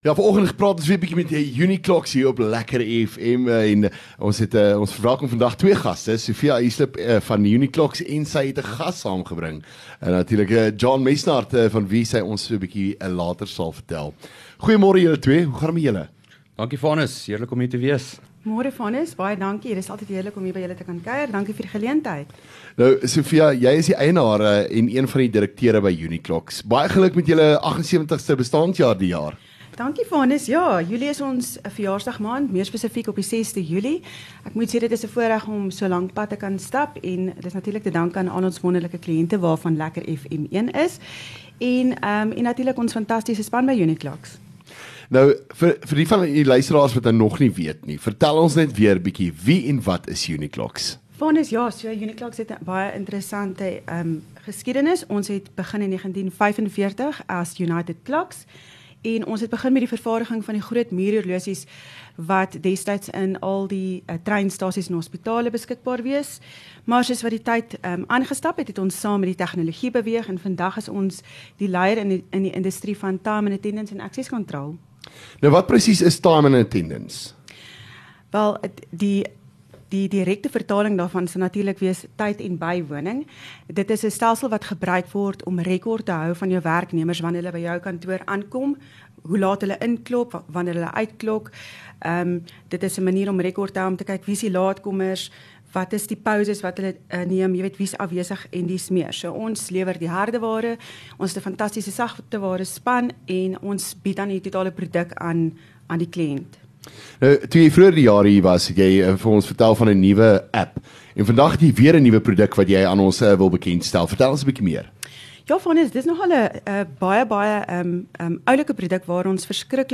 LEKKER FM | Onderhoude 12 Jul Uniclox